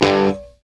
GTR STONE0DR.wav